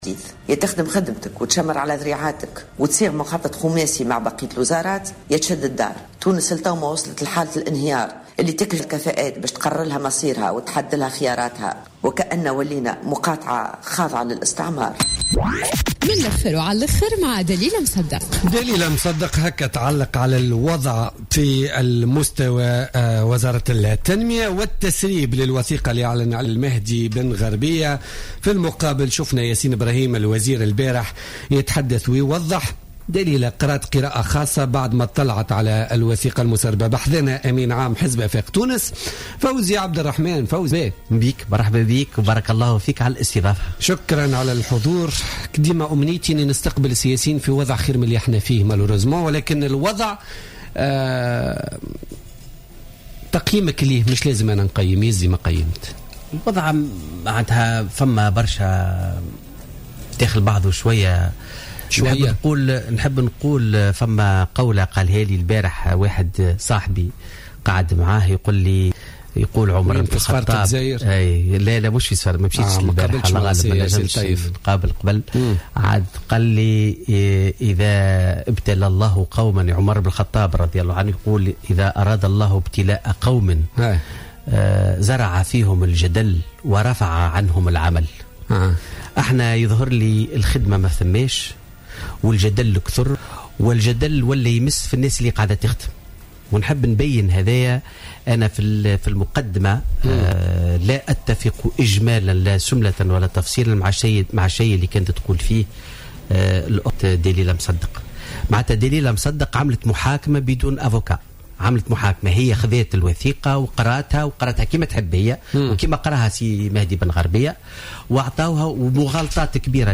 أكد أمين عام حزب افاق تونس فوزي عبد الرحمان، ضيف بوليتيكا اليوم الثلاثاء 3 نوفمبر 2015 في تعليق على الوثيقة المسربة من وزارة الاستثمار والتعاون الدولي والتي تخص اتفاقية تعاقد مع بنك أعمال فرنسي لهيكلة مخطط التنمية لسنة 2016/2020 ، أن ما ورد في تصريحات بعض السياسيين حول هذه الوثيقة والإيهام بأنها تمس من سيادة تونس لا أساس لها من الصحة وهي من قبيل المغالطات، وفق قوله.